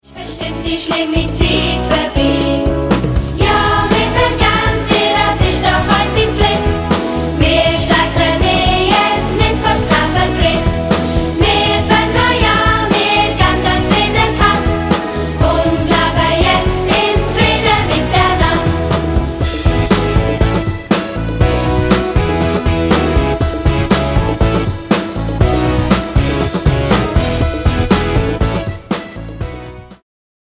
Minimusical für Grundschule und 1./2. Klasse